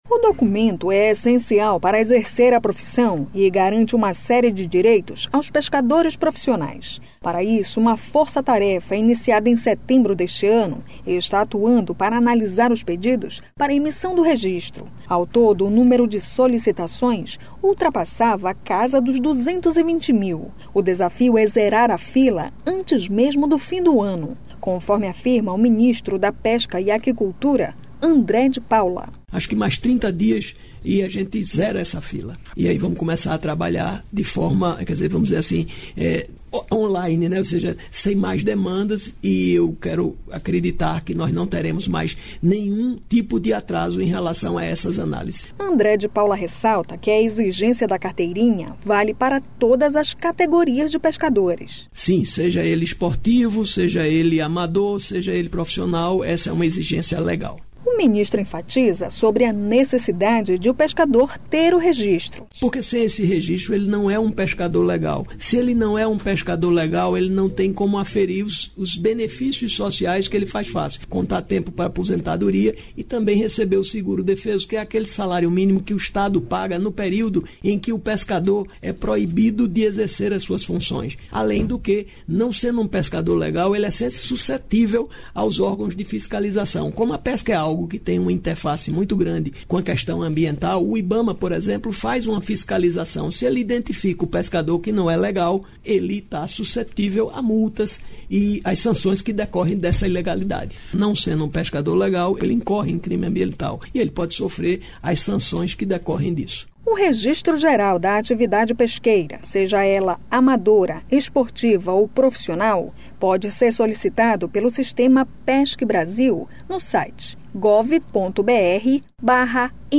Reportagem do dia